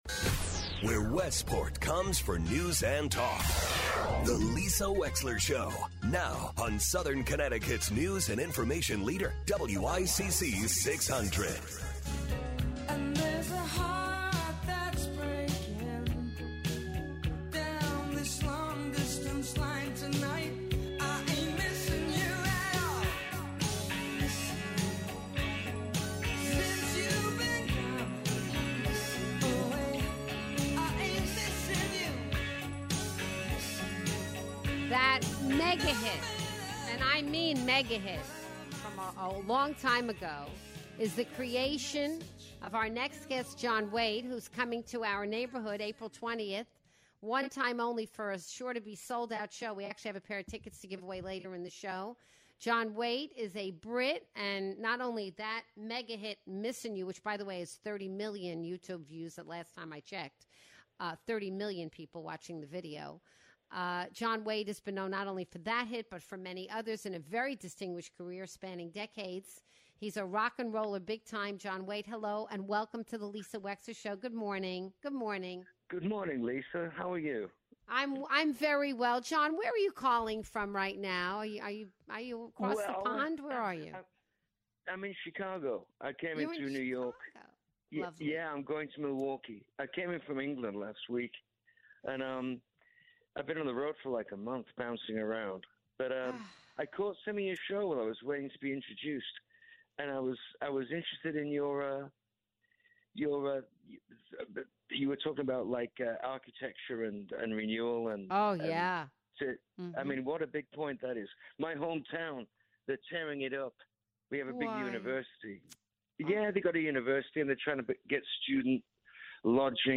talks with rockstar John Waite about architecture, life and music. John Waite is coming to the Wall Street Theater in Norwalk April 20th.